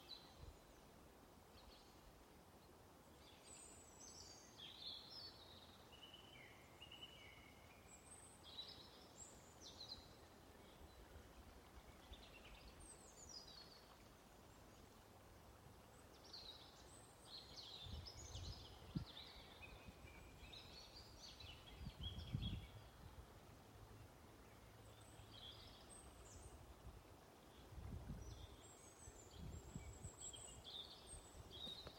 Sarkanrīklīte, Erithacus rubecula
Administratīvā teritorijaValkas novads
StatussDzied ligzdošanai piemērotā biotopā (D)